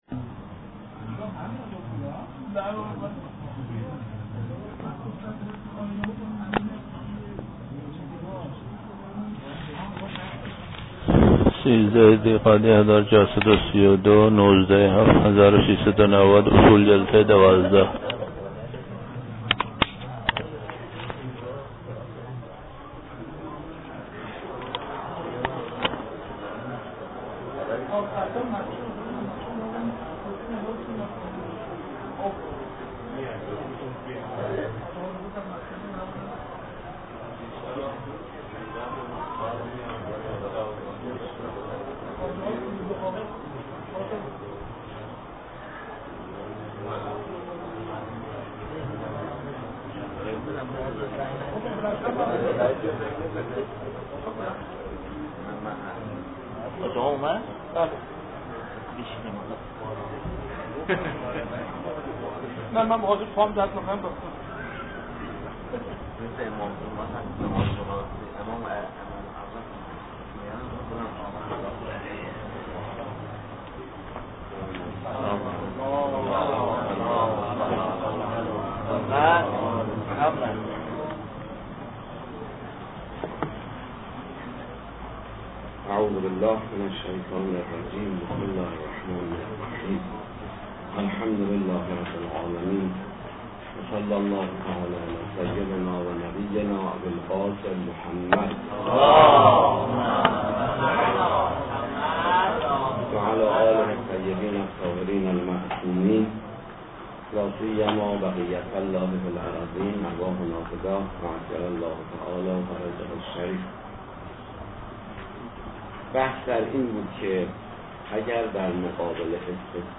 پخش آنلاین درس